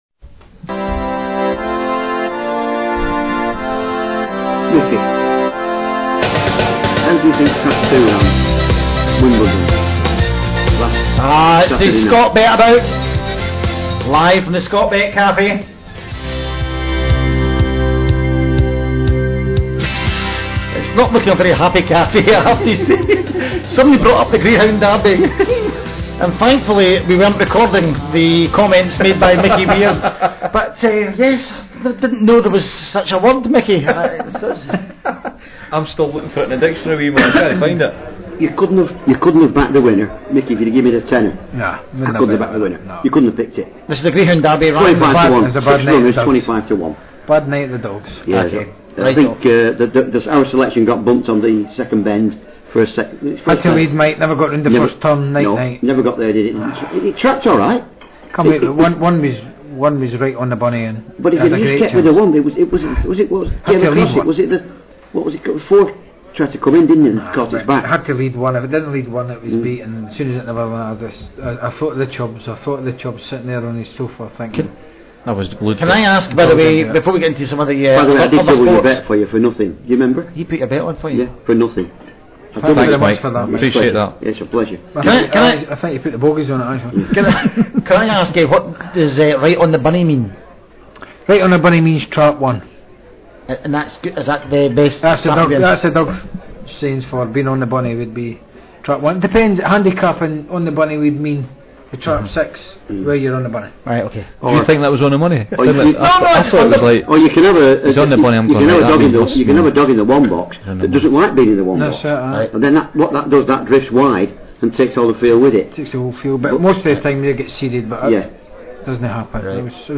Latest Episode Scotbet-a-Bout Episode 13 Download the latest episode Note: in some browsers you may have to wait for the whole file to download before autoplay will launch. Kickabout Scotland The Scotbet-a-Bout The Kickabout is the hit comedy sports show that became the longest running and most successful show on Scottish talk station, Talk107.
Top name guests from the world of sport will be joining the lads and ladette on the phone while the show is crammed full of features such as The Boot Room, Pub Chat and Confessions Of a Pole Dancer.